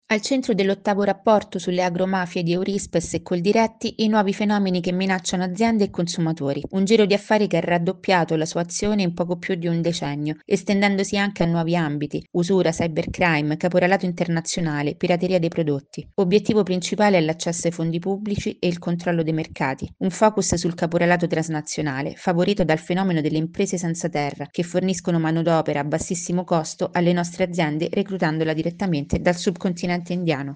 Fondi pubblici e nuovi mercati, sono gli obiettivi dei clan nell’8° Rapporto Eurispes-Coldiretti sulle Agromafie. Il servizio